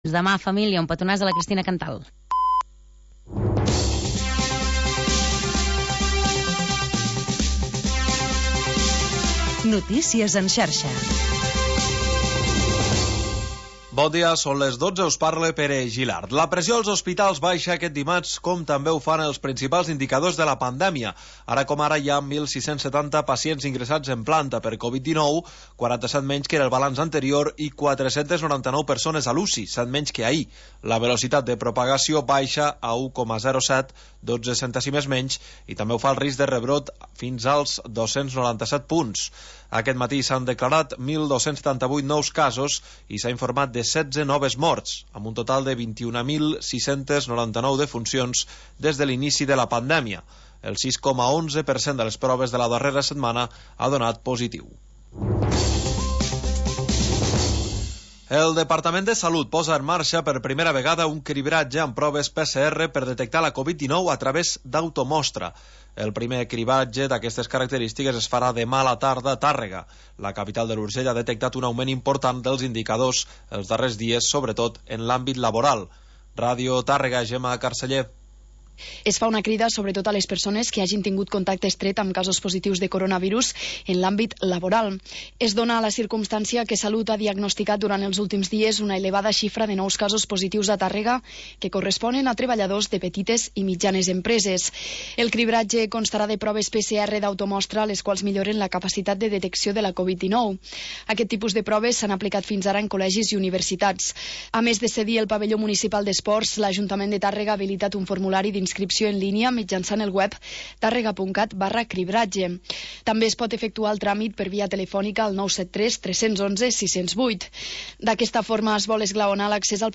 Tercera hora musical del magazín local d'entreteniment